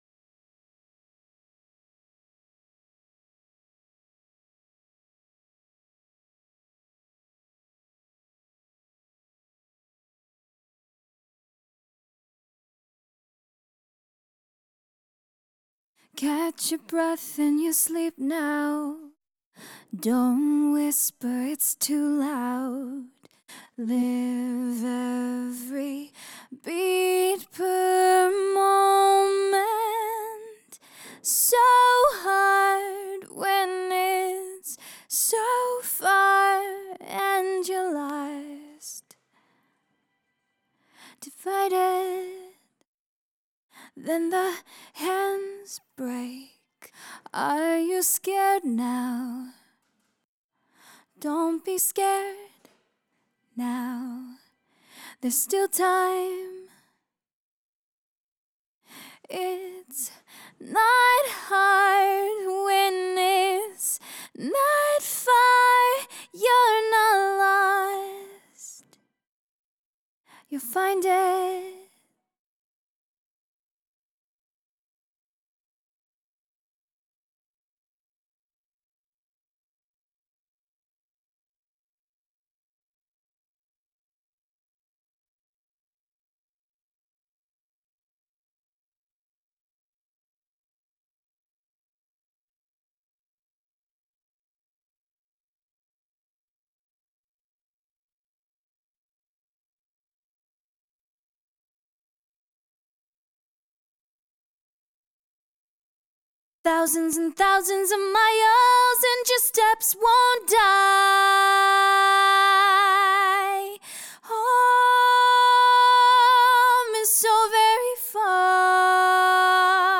The vocal stem can be